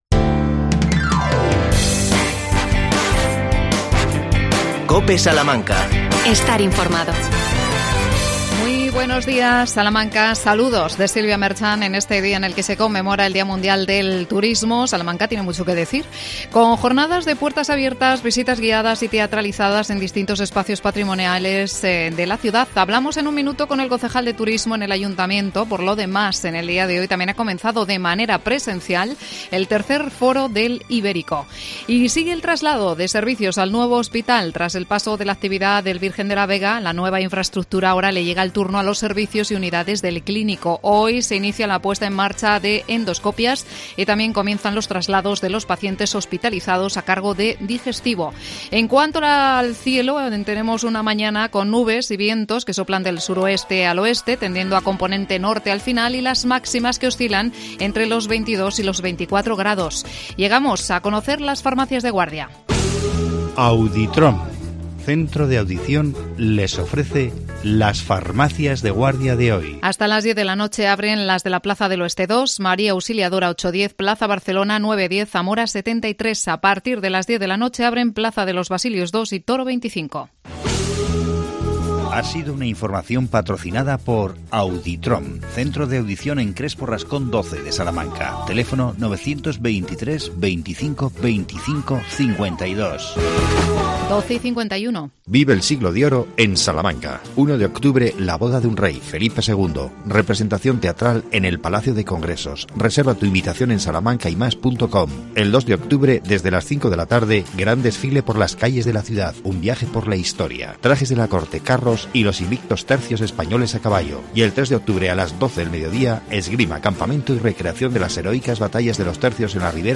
AUDIO: Día Mundial del Turismo. Entrevistamos al concejal Fernando Castaño.